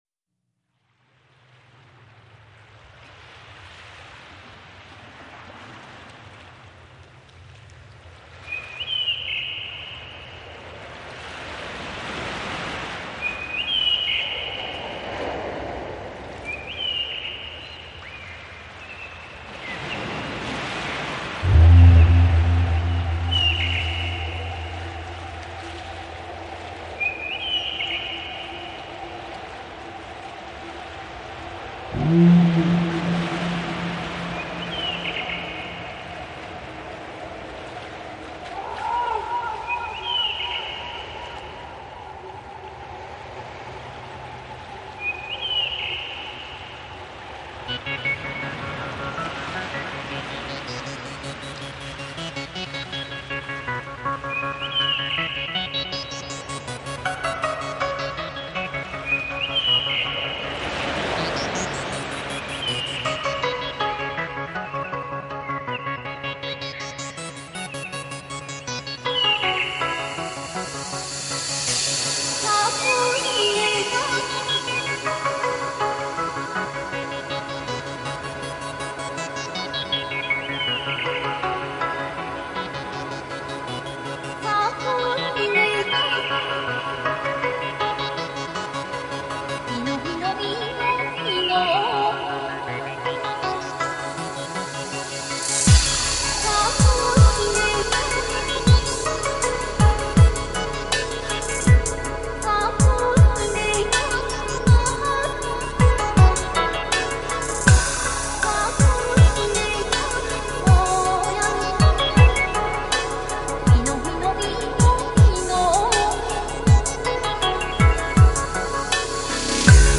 从新世纪音乐到世界音乐，从宗教冥想音乐到实验性融合的概念式音乐
从热带雨林的鸣虫水流，到北国永夜夜空中树梢 高挂的明月。